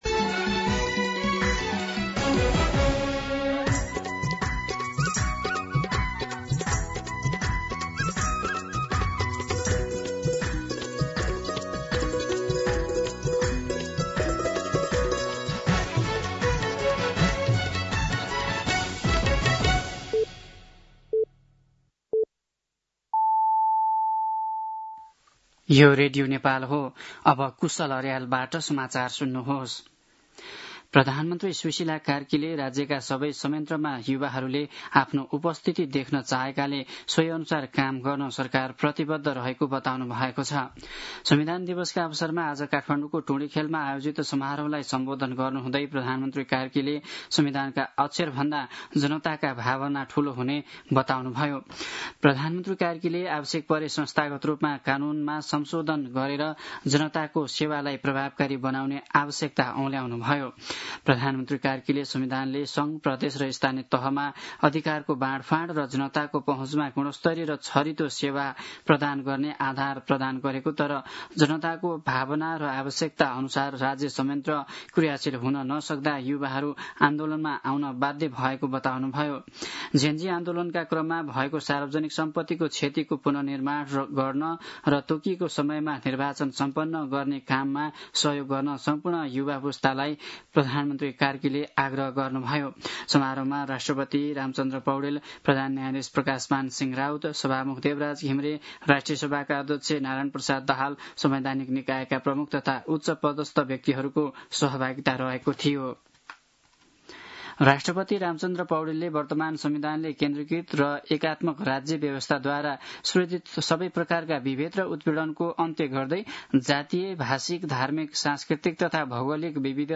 दिउँसो ४ बजेको नेपाली समाचार : ३ असोज , २०८२
4-pm-Nepali-News-2.mp3